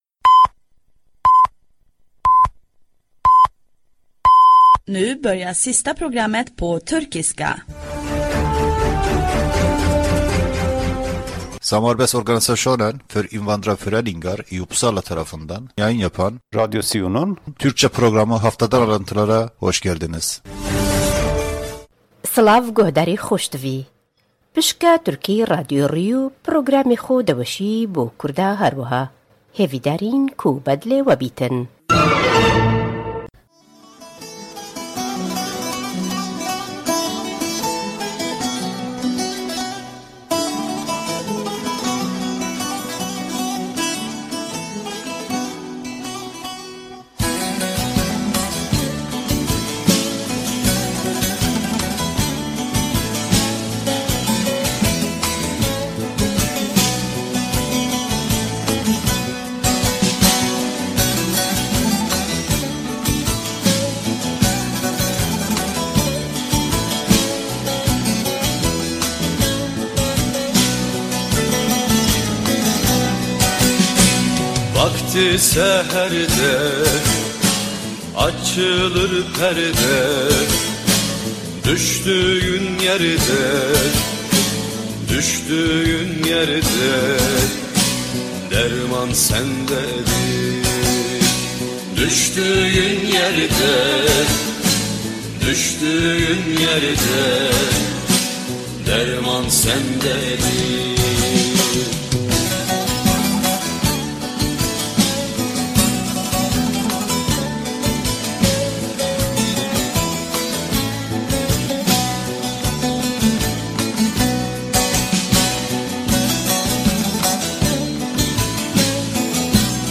Radyo SİU bünyesinde yayın yapan bir programdır. Program; SIU´nun hafta içi faalıyelerıyle ilgili haberler ile lokal haberler ve İsvec genelinden haberleri içerir.